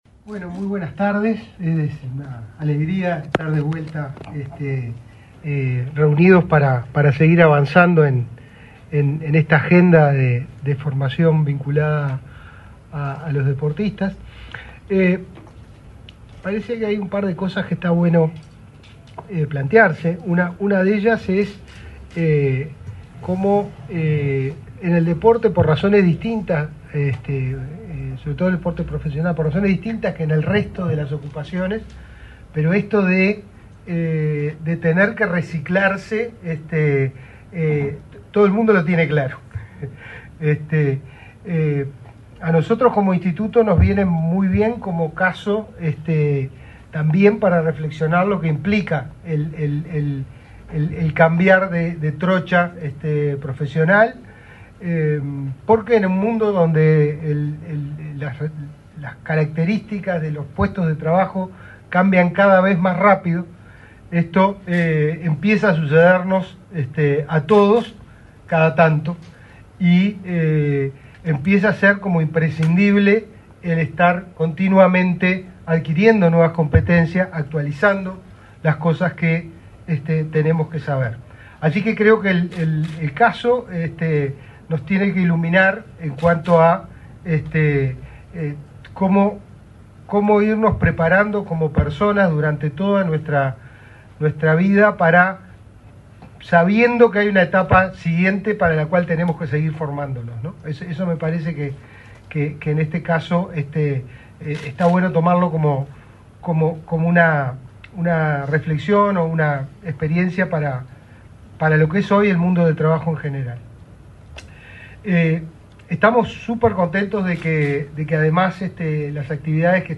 Palabra de autoridades en acto de firma de convenio entre el Inefop, la SND y la MUFP
En la oportunidad, el secretario del Deporte, Sebastián Bauzá, y el director del Inefop, Pablo Darscht, realizaron declaraciones.